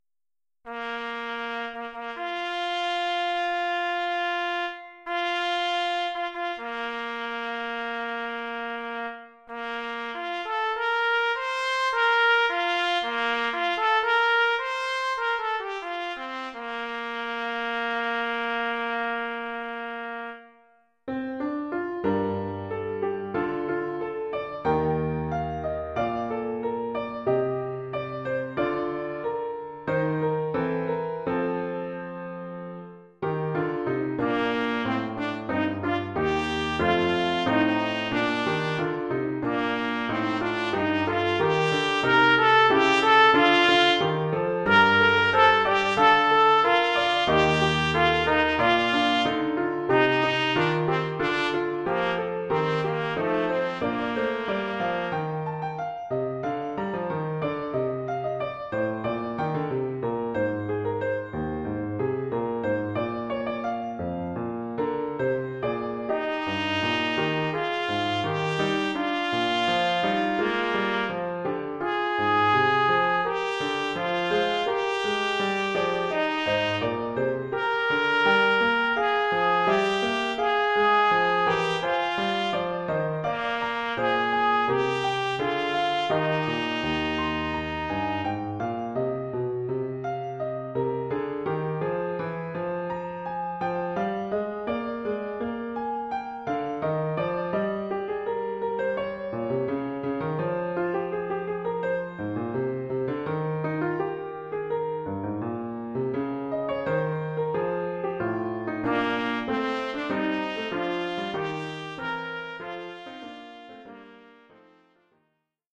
Formule instrumentale : Trompette et piano
Oeuvre pour trompette ou cornet
ou bugle et piano..
Niveau : débutant.